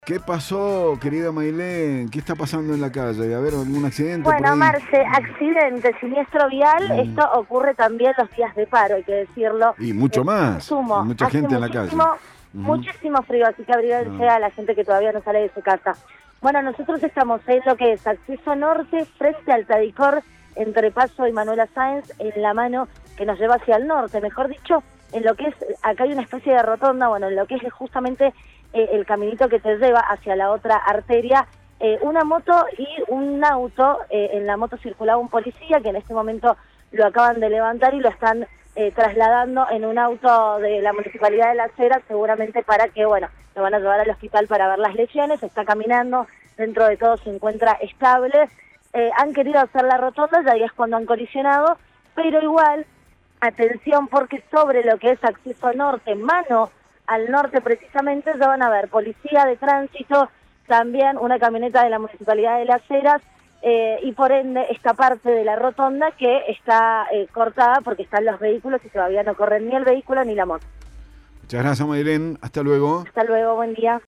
Móvil de LVDiez- Accidente de tránsito en Acceso Norte, en la rotonda frente al Tadicor